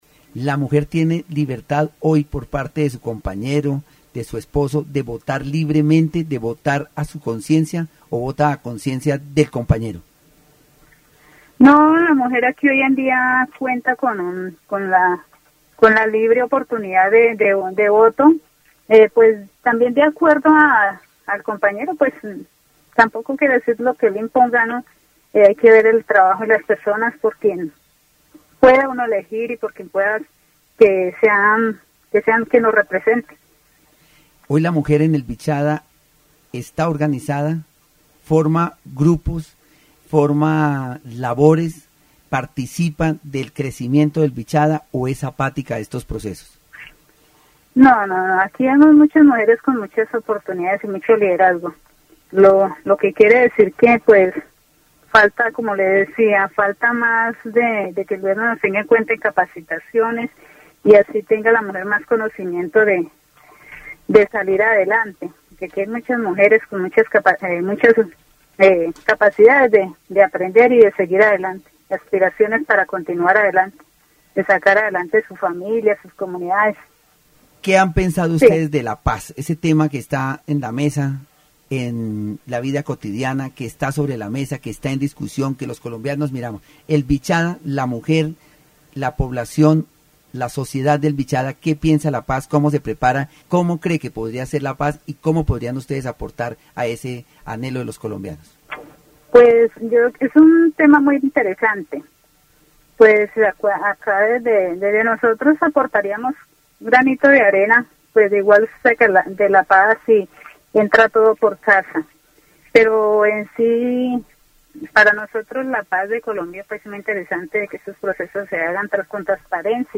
dc.descriptionEntrevista sobre la libertad de voto y la participación de las mujeres en la sociedad de Vichada, destacando la necesidad de mayor capacitación y apoyo para el liderazgo femenino, así como la importancia de la paz y el papel de la comunidad en su construcción.
dc.subject.lembProgramas de radio